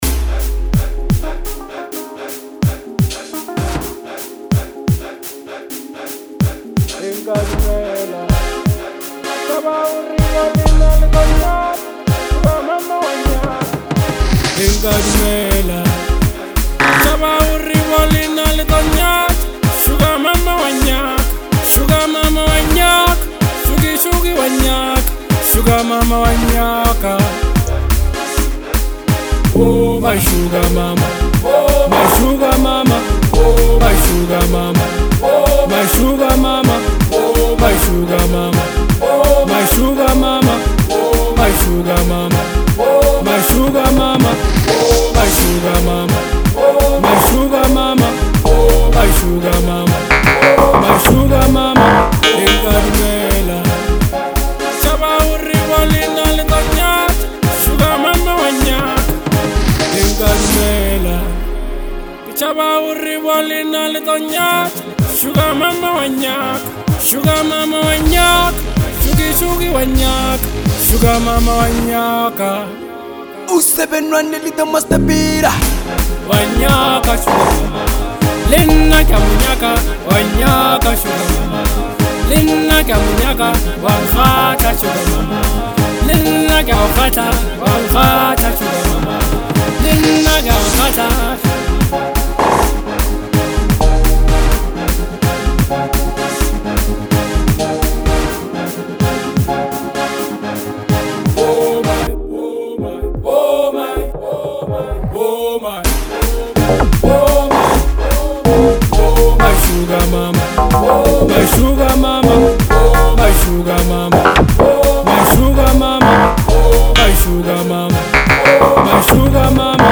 spicy and playful Lekompo single
The beat is vibey